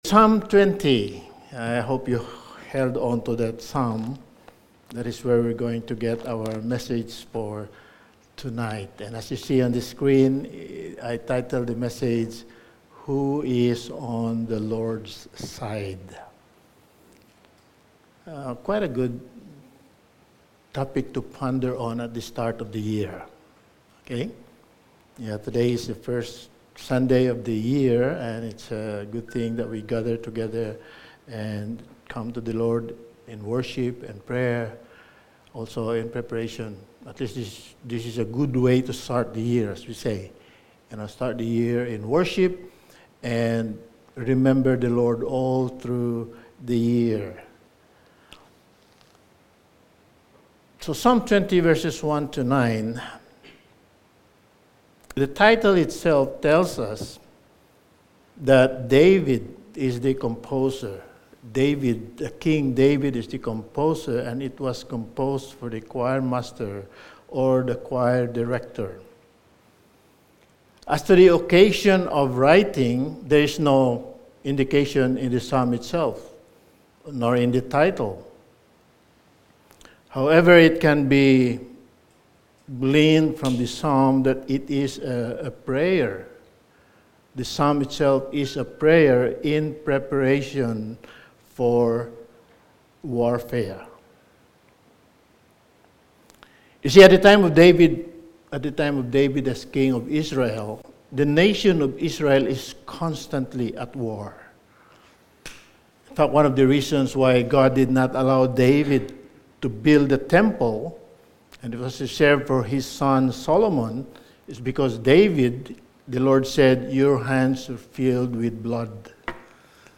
Sermon
Psalm 20:1-9 Service Type: Sunday Evening Sermon 22 « The Day of the Lord